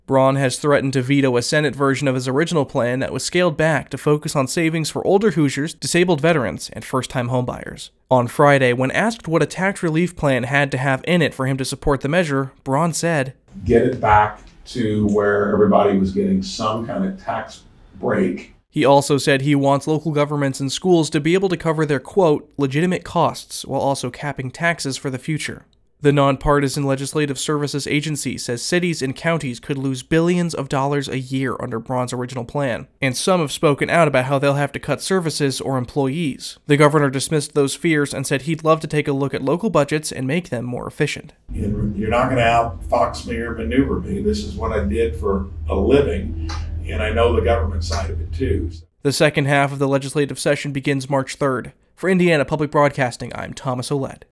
IPR News